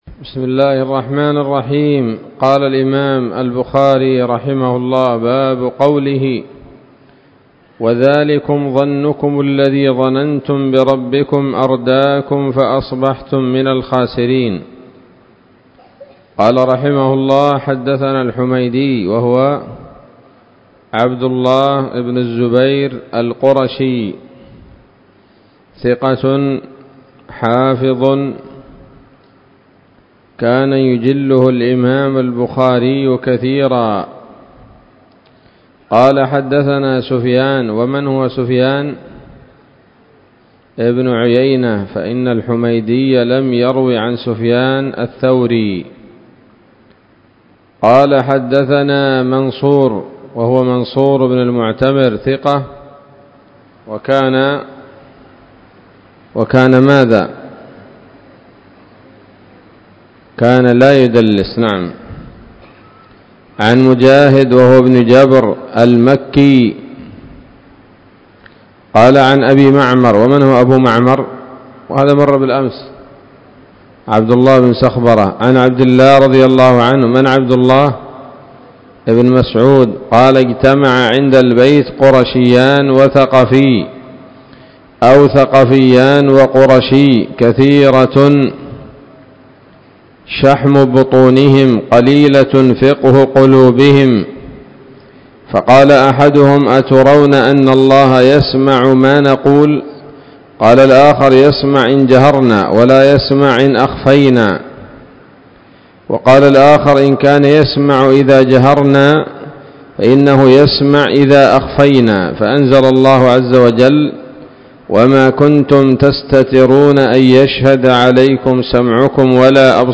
الدرس الثالث والعشرون بعد المائتين من كتاب التفسير من صحيح الإمام البخاري